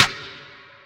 SNARE 27.wav